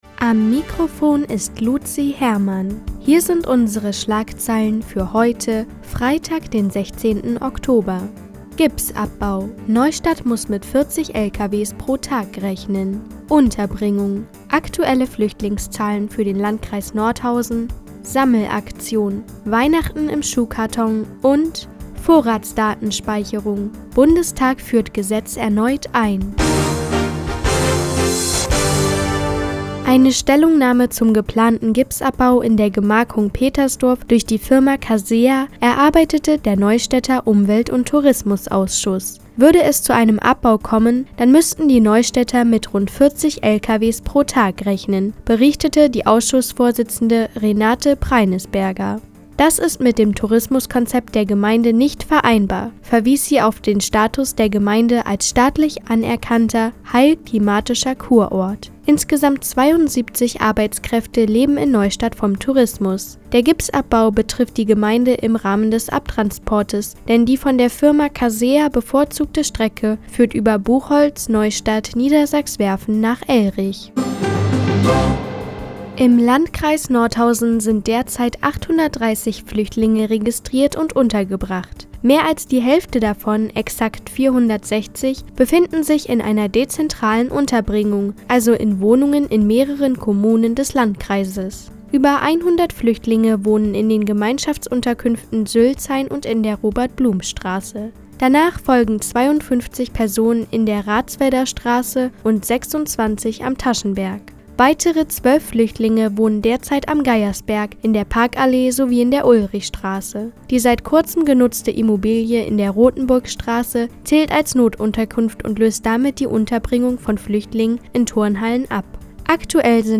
Fr, 17:00 Uhr 16.10.2015 Neues vom Offenen Kanal Nordhausen „Der Tag auf die Ohren“ Seit Jahren kooperieren die Nordthüringer Online-Zeitungen, und der Offene Kanal Nordhausen. Die tägliche Nachrichtensendung des OKN ist jetzt hier zu hören.